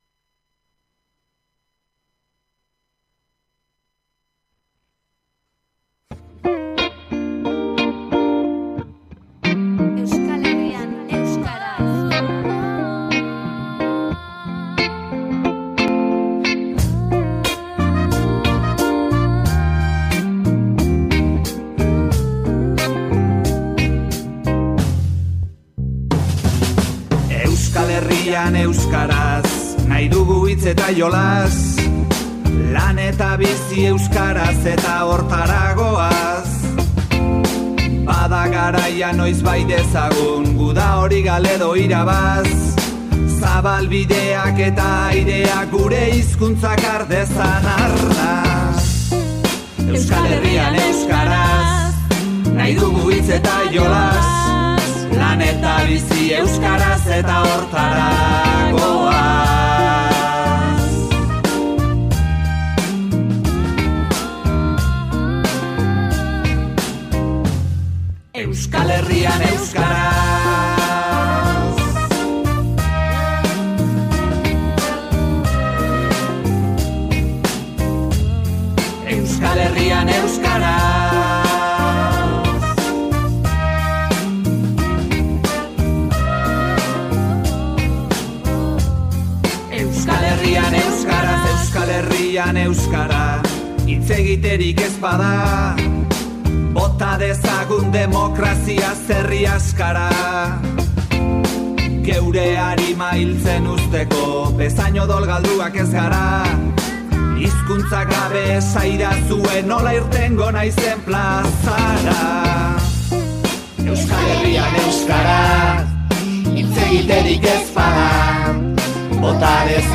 Azken ANTXOEN BERTSOA gaueko ordutegian burutu dugu, 22:00tatik aurrera, hain zuzen ere. Saioan zehar, azaroak 12n Baionan ospatu zen Xilabako final handiaz aritu gara eta zonbait bertsu entzun ditugu.